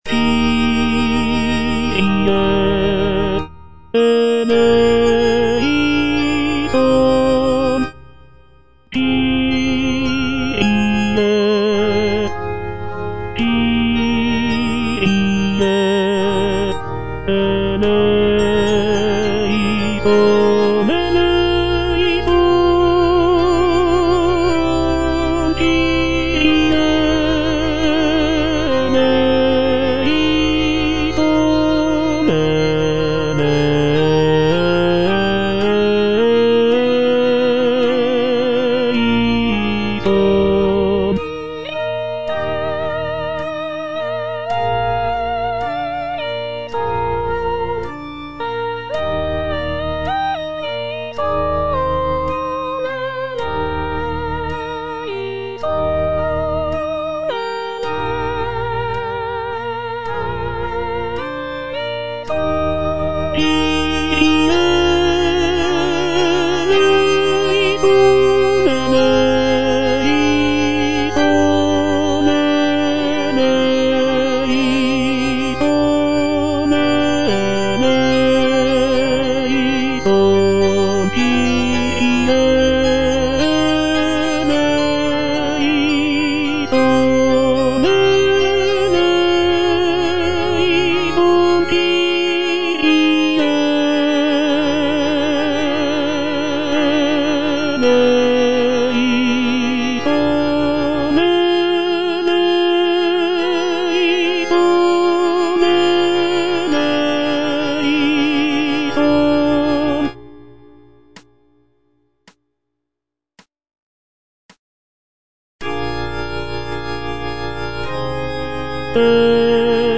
C.M. VON WEBER - MISSA SANCTA NO.1 Kyrie eleison - Tenor (Voice with metronome) Ads stop: auto-stop